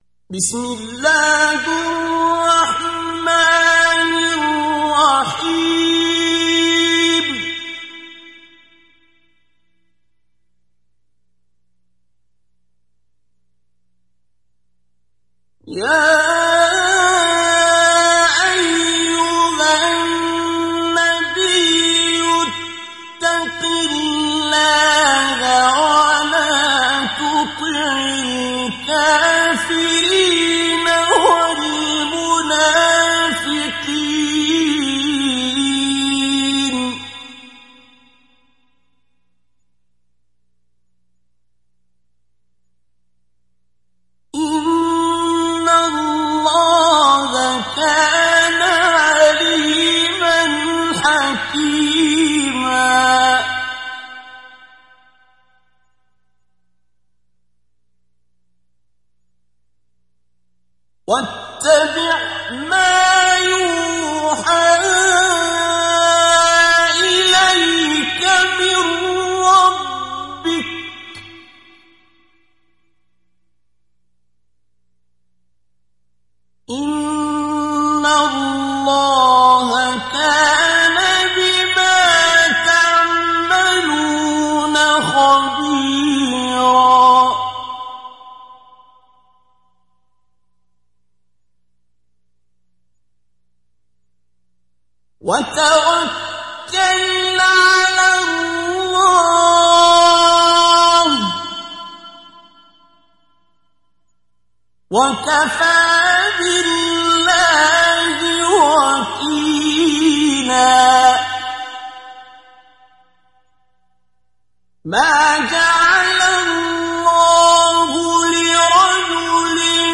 تحميل سورة الأحزاب mp3 بصوت عبد الباسط عبد الصمد مجود برواية حفص عن عاصم, تحميل استماع القرآن الكريم على الجوال mp3 كاملا بروابط مباشرة وسريعة
تحميل سورة الأحزاب عبد الباسط عبد الصمد مجود